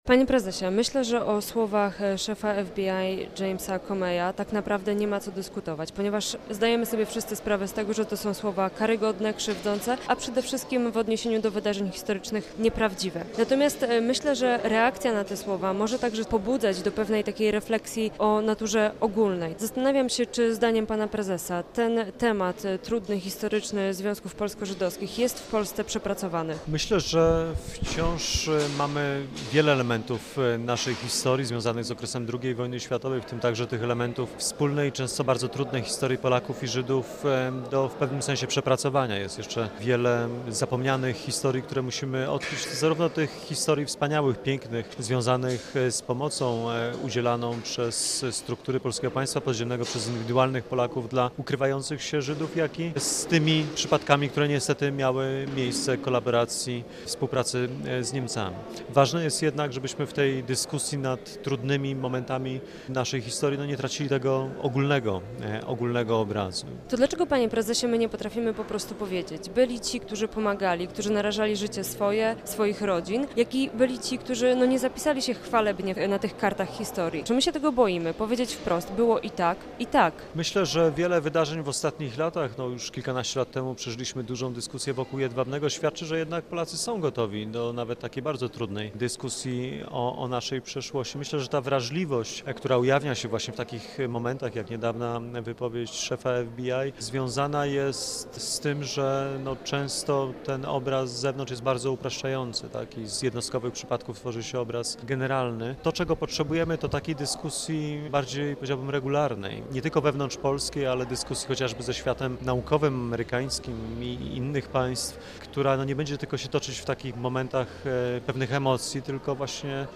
Posłuchaj i dowiedz się więcej: Nazwa Plik Autor Rozmowa z dr Łukaszem Kamińskim, prezesem IPN audio (m4a) audio (oga) Warto przeczytać Skrótami w góry (7 – 17 lipca) 3 lipca 2025 Pogoda na czwartek.